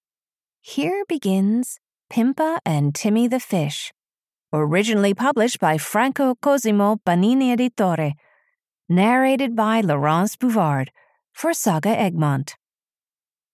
Pimpa and Timmy the Fish (EN) audiokniha
Ukázka z knihy